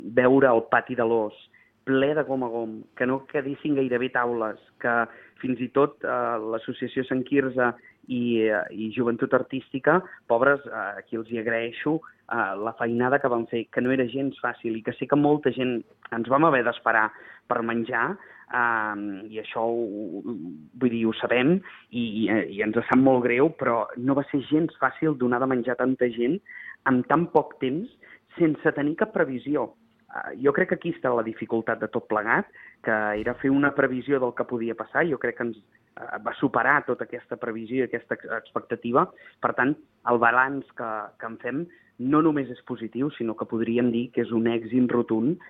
El titular de Cultura de l’Ajuntament de Calella, Josep Grima, ha fet balanç de la celebració, ha dit que l’esdeveniment va superar totes les previsions i ha agraït la feina de les entitats.